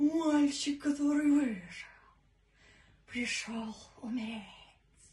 Жен, Пародия(Волан-де-Морт)
Мои демо были записаны на самые разные устройства, чтобы вы могли ознакомиться со звучанием моего голоса.